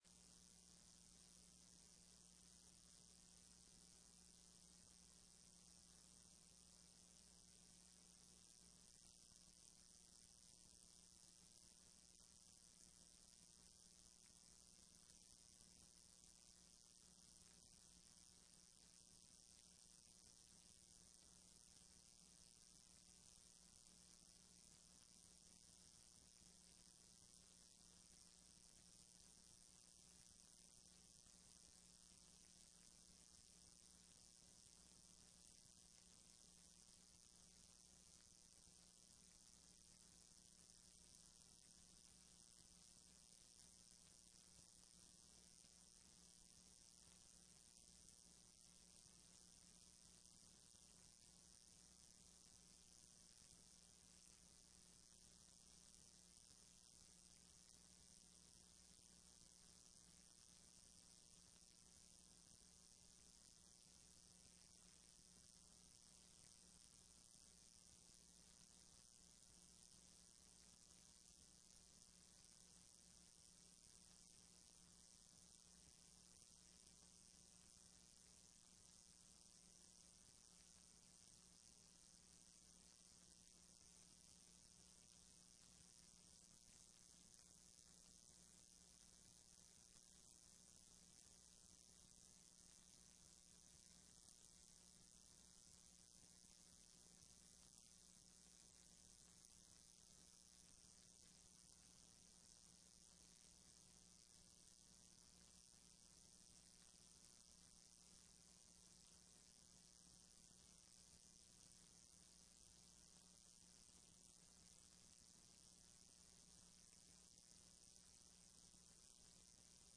TRE-ES - Sessão 10.06.15